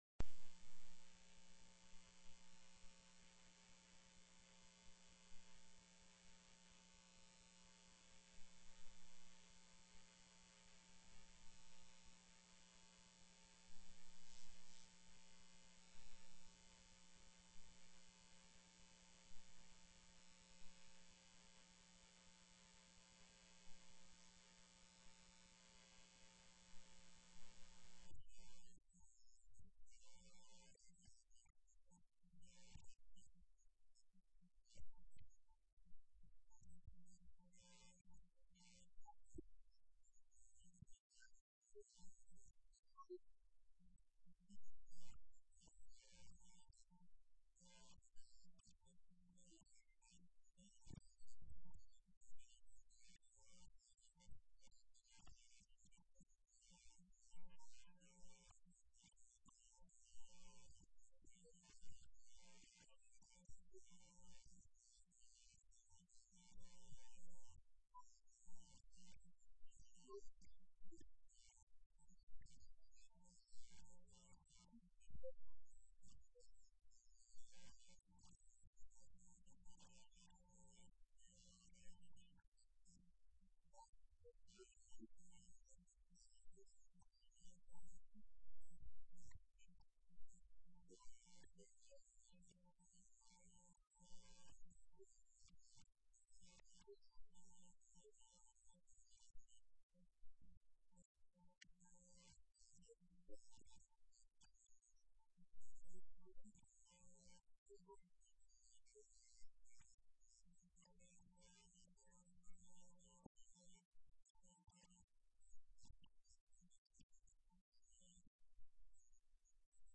02/25/2010 01:30 PM House FINANCE